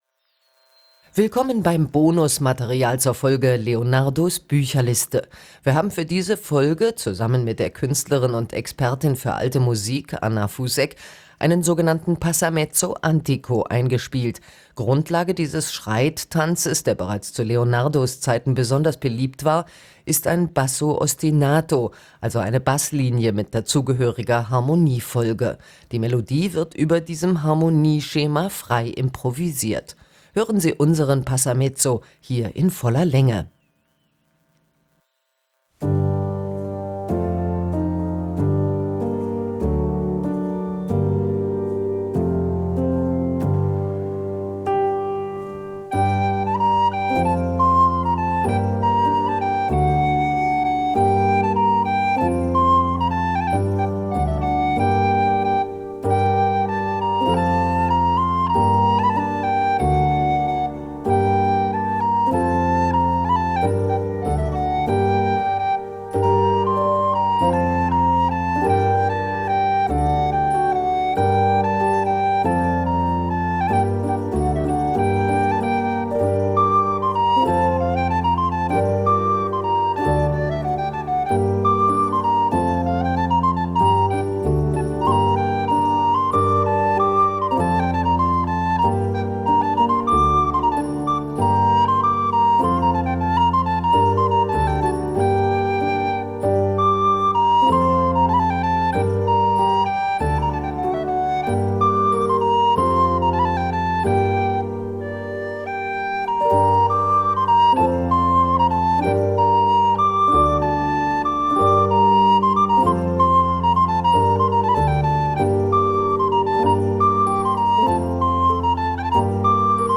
Wir haben für diese Folge einen sogenannten Passamezzo antico eingespielt. Dieser Schreittanz, der bereits zu Leonardos Zeiten besonders beliebt war, besteht aus einem Basso ostinato - also einer Basslinie mit dazugehöriger Harmoniefolge. Die Melodie wird über diesem Harmonieschema dazu improvisiert.